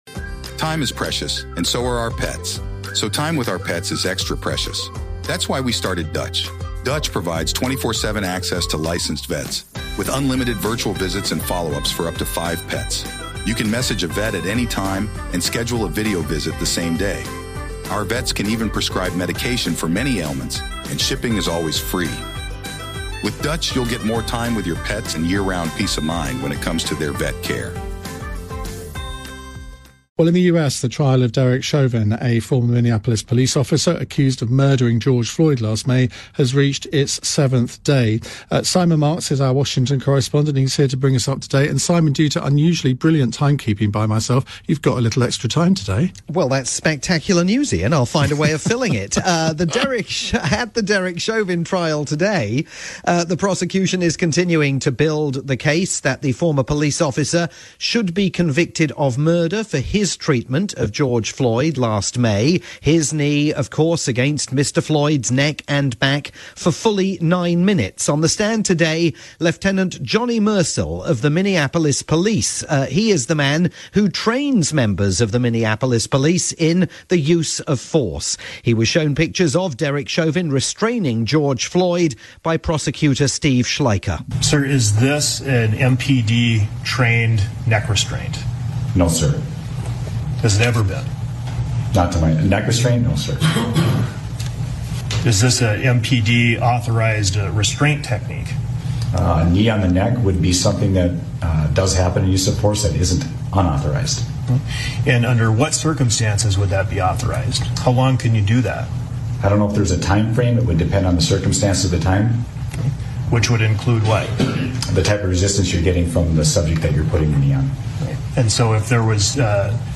nightly roundup for Iain Dale's programme on LBC.